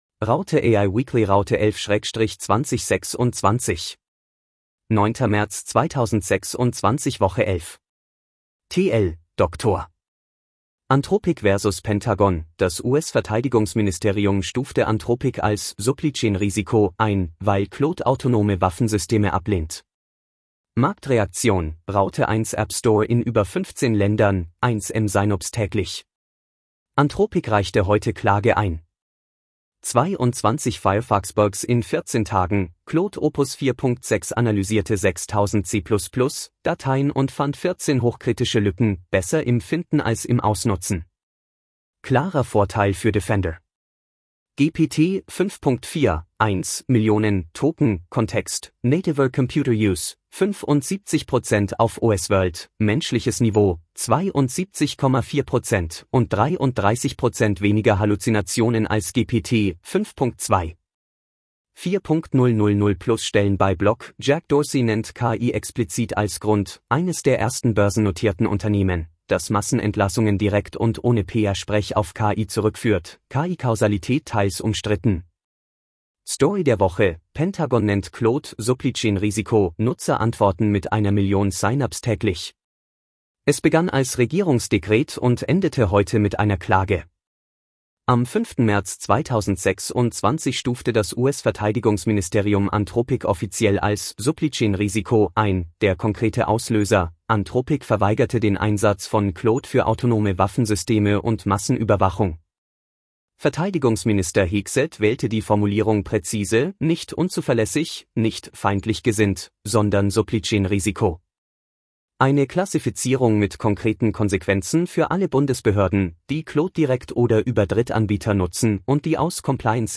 Vorgelesen mit edge-tts (de-DE-ConradNeural)